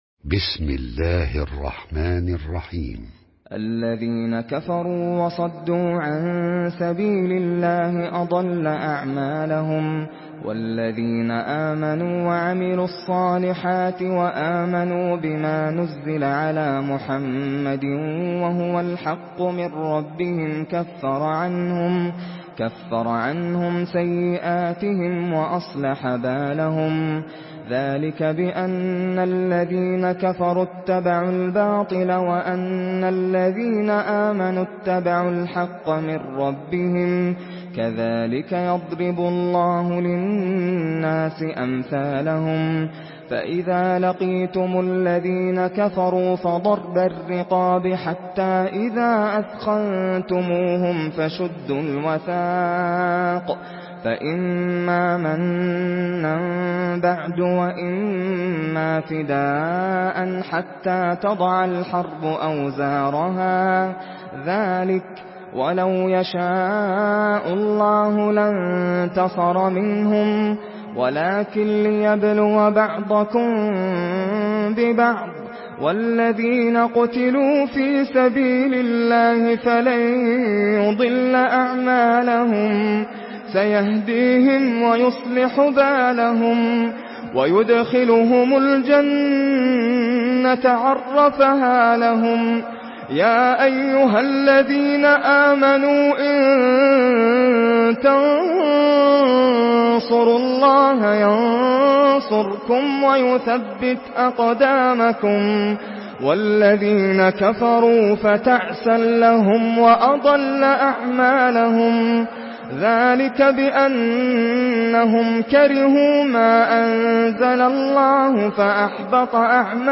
Surah মুহাম্মাদ MP3 by Nasser Al Qatami in Hafs An Asim narration.